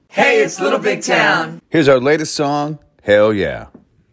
Audio / LINER Little Big Town (Hell Yeah) 2